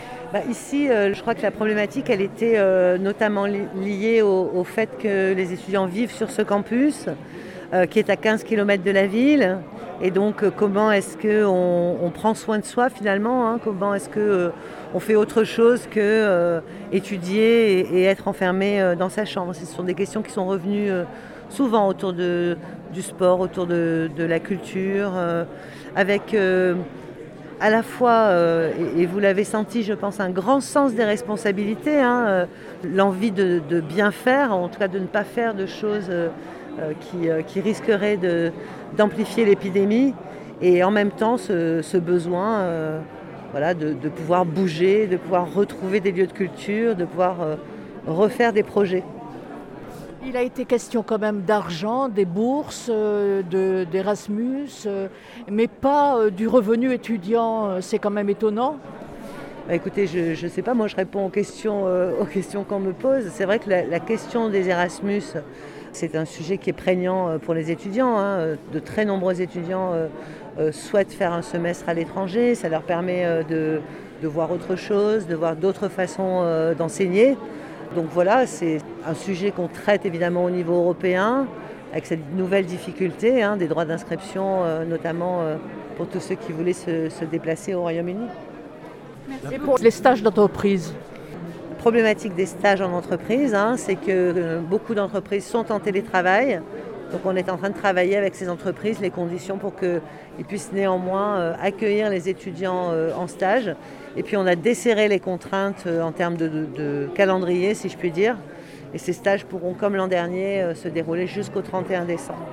son_copie_petit-473.jpgFrédérique Vidal, ministre de l’Enseignement supérieur, de la Recherche et de l’Innovation s’est entretenue une heure durant avec une trentaine d’étudiants qui n’étaient pas issus d’organisations syndicales étudiantes.